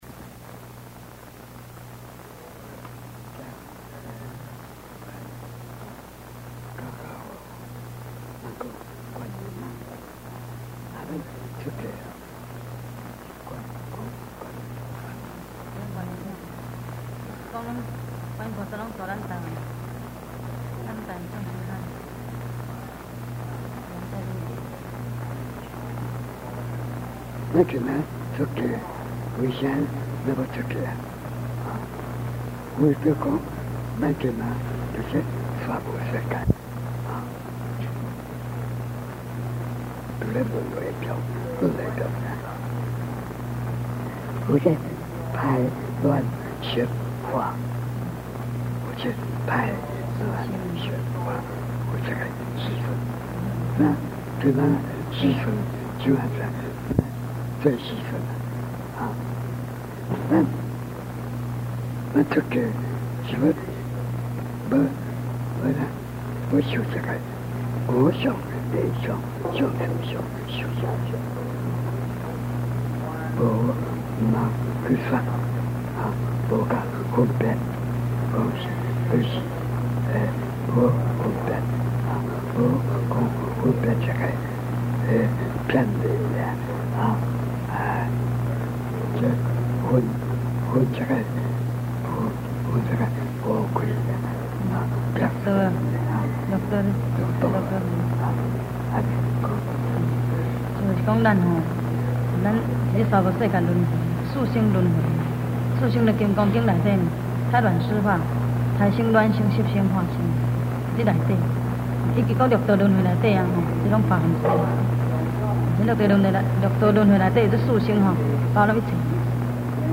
佛學講座
開示-現場錄音